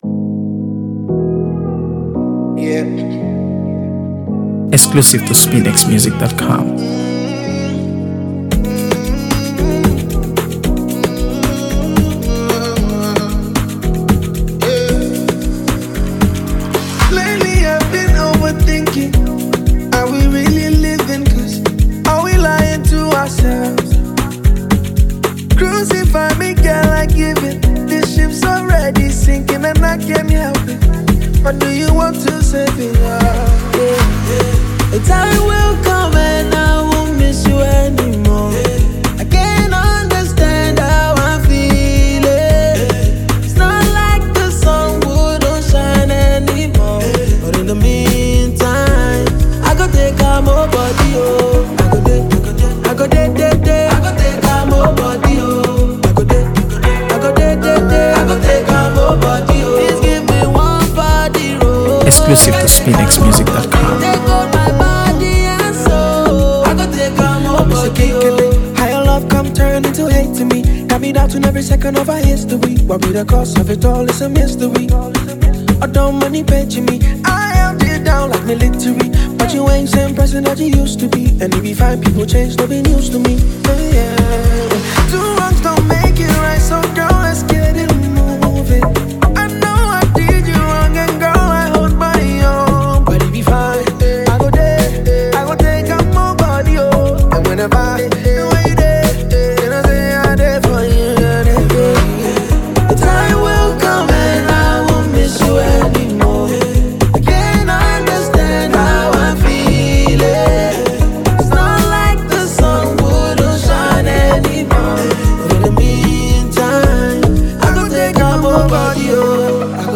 AfroBeats | AfroBeats songs
” a smooth and infectious tune featured on his latest album